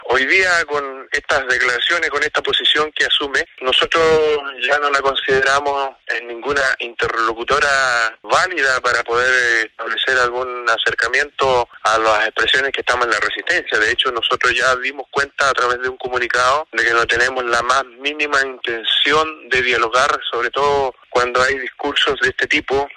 Las palabras del líder de la CAM las efectuó en la comunidad Buta Rincon de la comuna de Lumaco, que está en un proceso de recuperación de tierras.